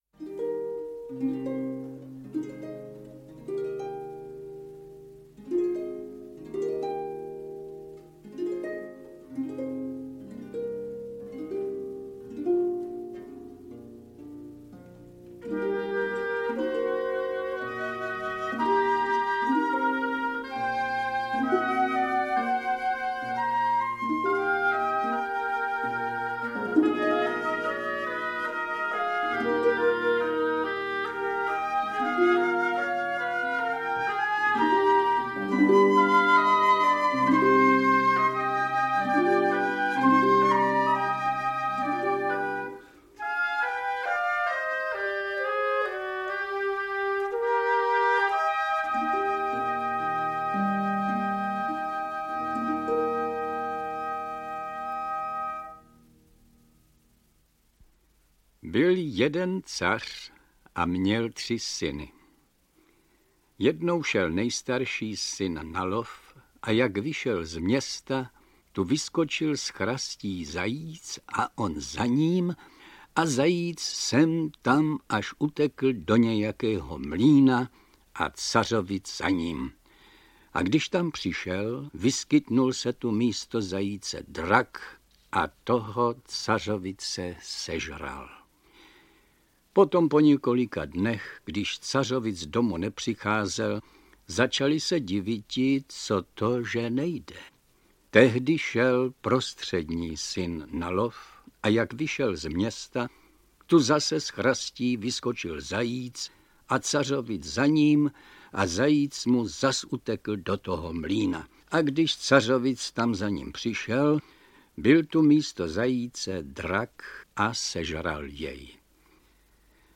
Audiokniha
Čte: František Smolík